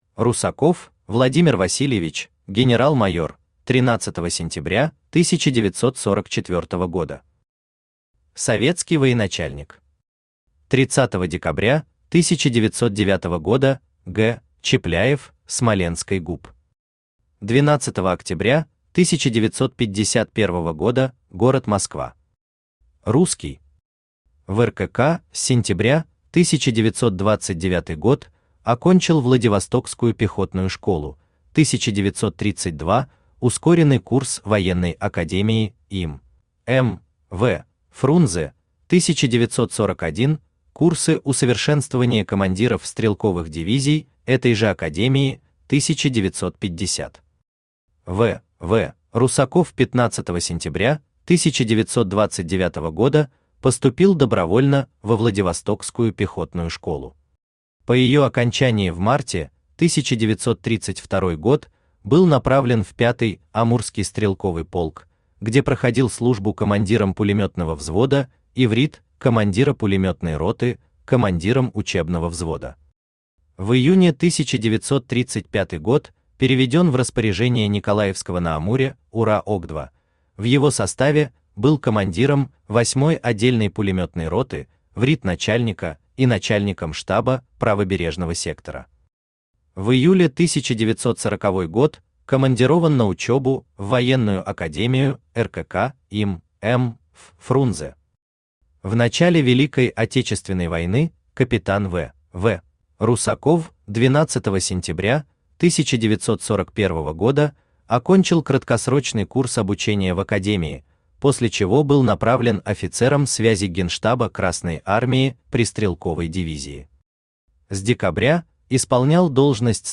Аудиокнига Все генералы Сталина. Том 52 | Библиотека аудиокниг
Том 52 Автор Денис Соловьев Читает аудиокнигу Авточтец ЛитРес.